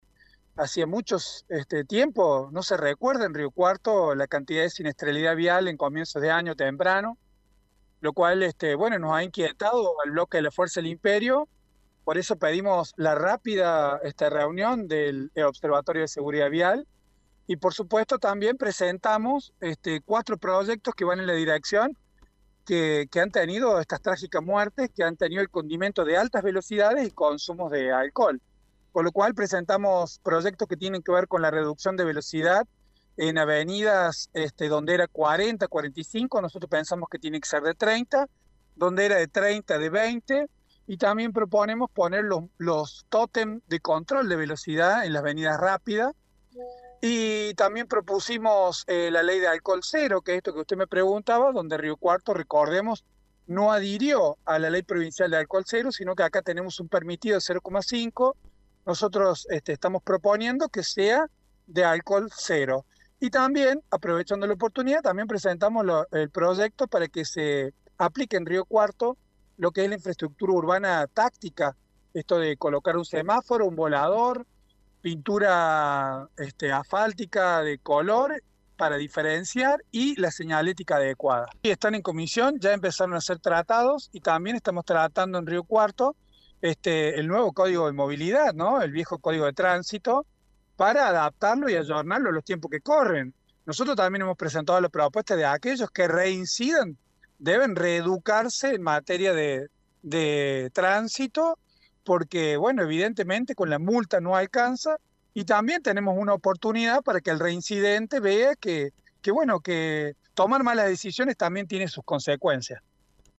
Franco Miranda, concejal de la oposición, dijo que la intención de este proyecto se vincula con la alta siniestralidad registrada durante las primeras semanas del año.
El edil explicó algunas características de esta iniciativa que se analiza en comisión.